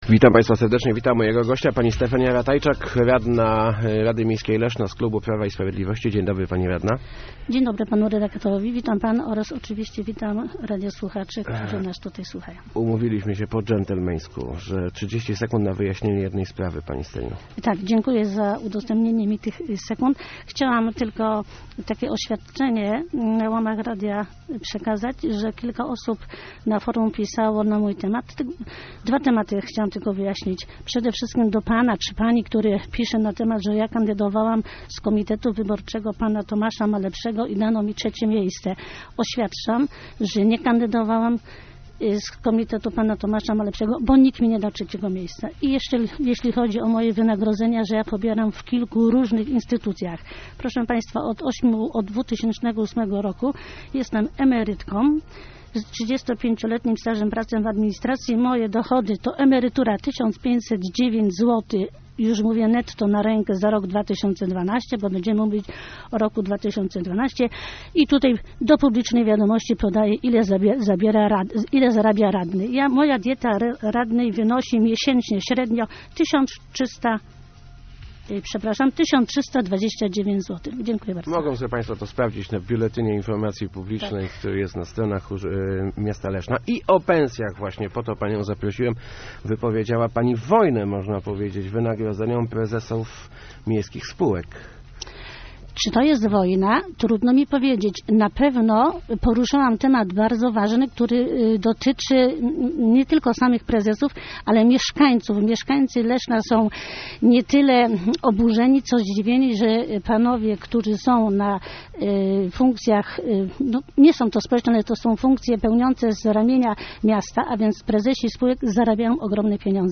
Prezesi leszczyńskich spółek powinni zarabiać o połowę mniej - mówiła w Rozmowach Elki Stefania Ratajczak. Radna PiS jest przekonana, że przełożyłoby się to na rachunki płacone przez mieszkańców miasta.